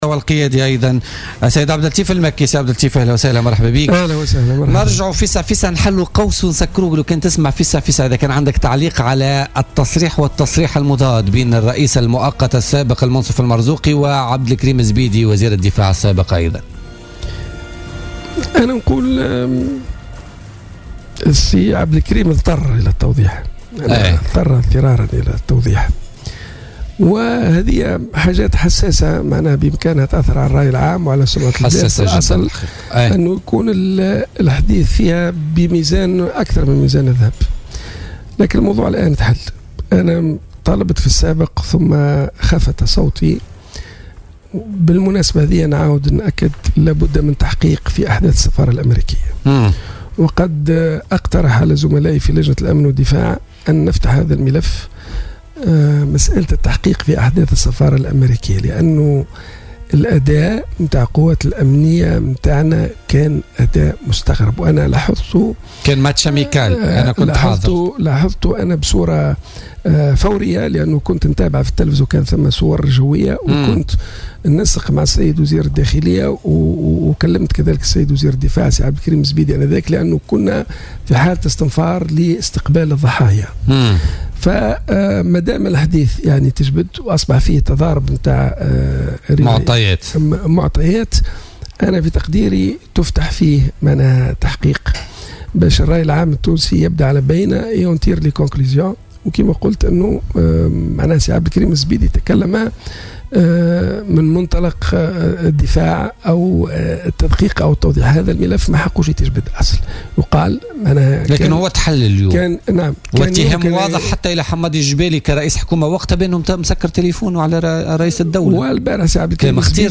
وقال ضيف "بوليتيكا" على "الجوهرة أف أم" إن وزير الدفاع الأسبق عبد الكريم الزبيدي "اضطر اضطرارا" لتوضيح هذا الموضوع الحسّاس، بحسب تعبيره.